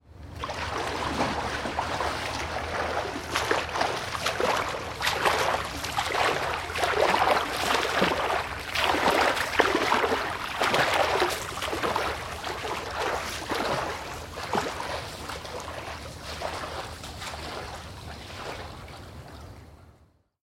Звуки плавания
Шум плеска воды на реке или озере